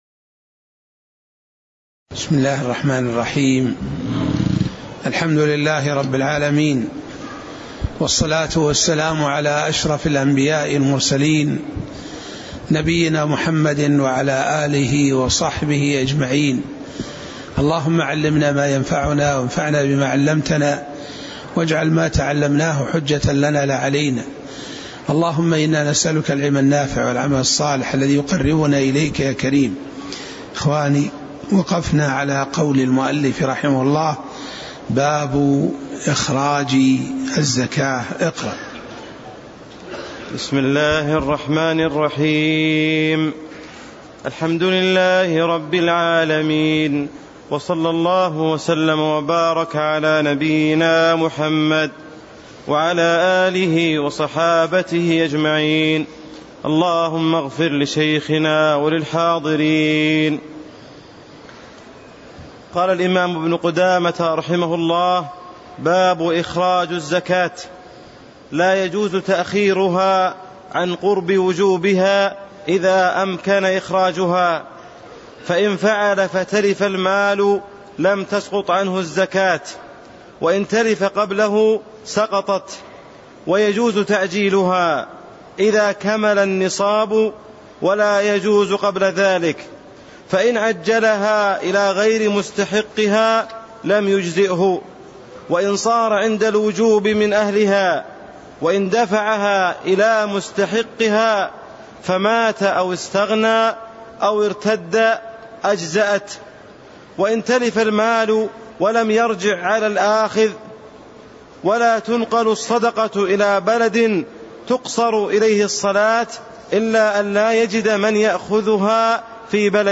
تاريخ النشر ١٠ شعبان ١٤٣٤ هـ المكان: المسجد النبوي الشيخ